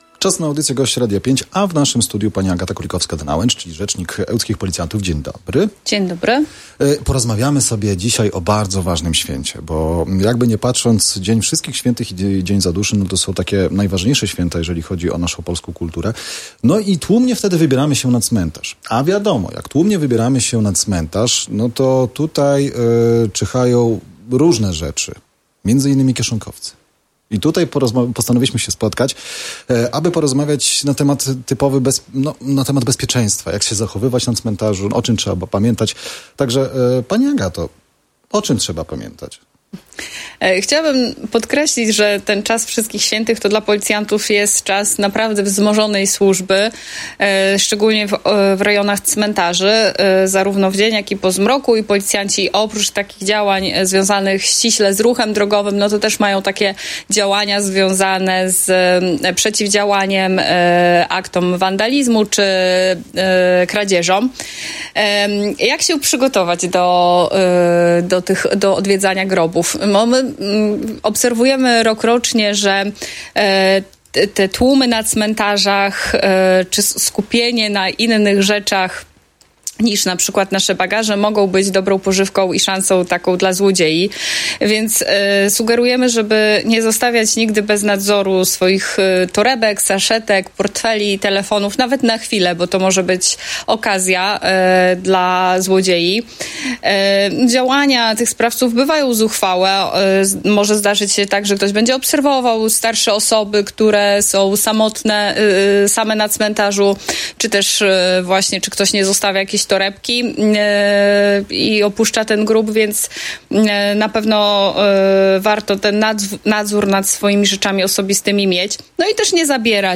Gość Radia 5 Ełk